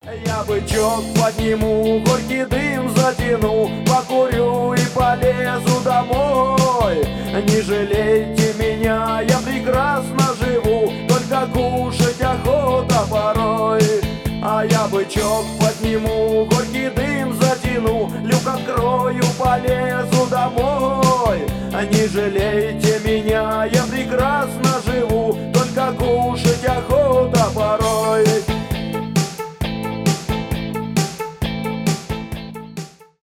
грустные , рок , 90-е , русские , панк-рок